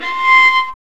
Index of /90_sSampleCDs/Roland L-CD702/VOL-1/STR_Viola Solo/STR_Vla Harmonx